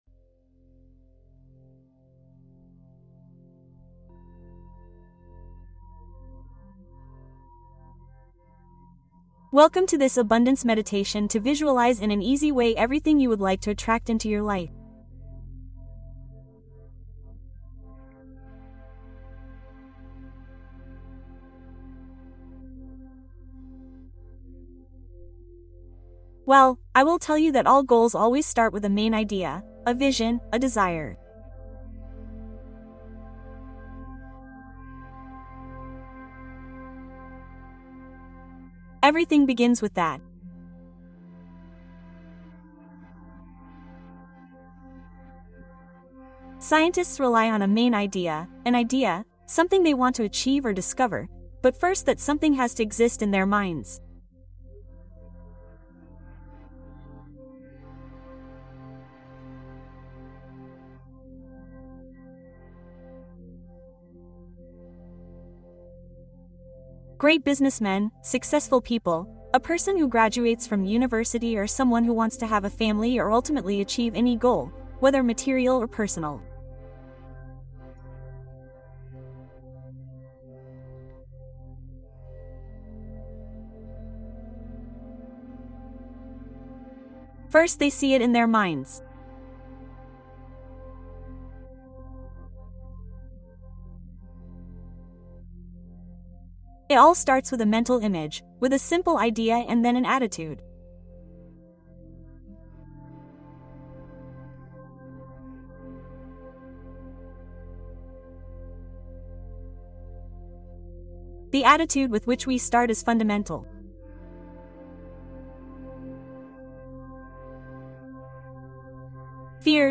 Meditación para atraer abundancia y manifestar tus metas